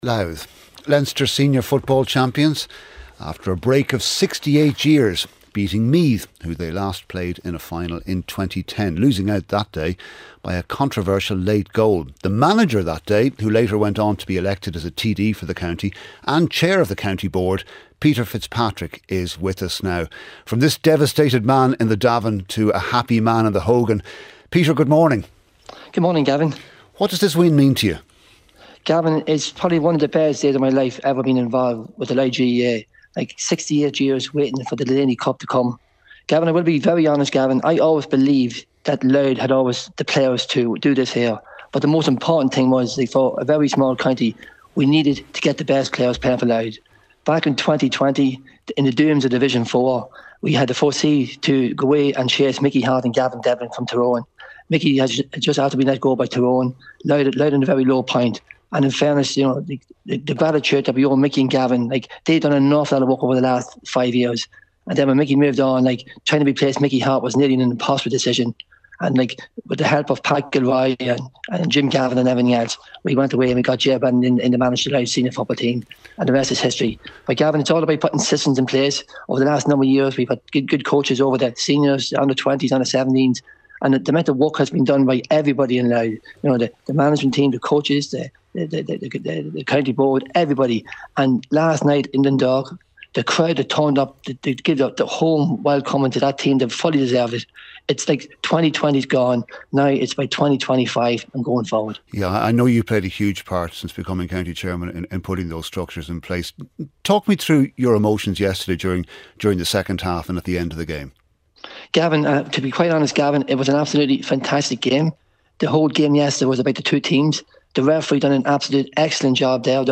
Bez rejestrowania się i instalowania czegoś. 8am News Bulletin. Weather Forecast.